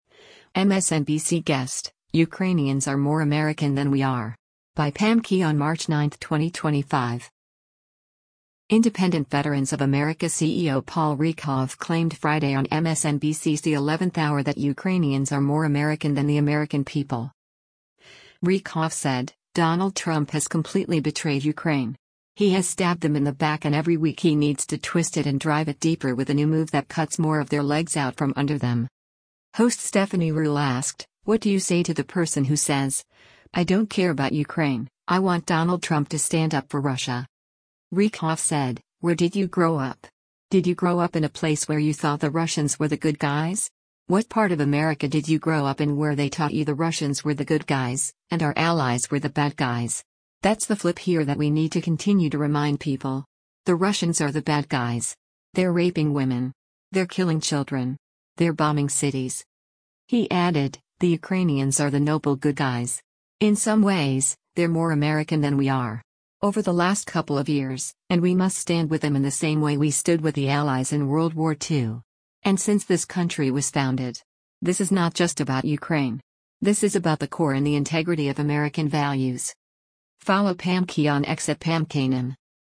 Independent Veterans of America CEO Paul Rieckhoff claimed Friday on MSNBC’s “The 11th Hour” that Ukrainians are “more American” than the American people.
Host Stephanie Ruhle asked, “What do you say to the person who says, I don’t care about Ukraine, I want Donald Trump to stand up for Russia?”